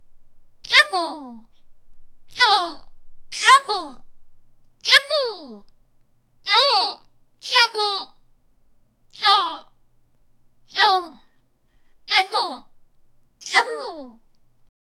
sexy-girl-says-noykinoy-y-56g4bi5k.wav